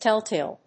音節téll・tàle 発音記号・読み方
/ˈtɛˌltel(米国英語), ˈteˌlteɪl(英国英語)/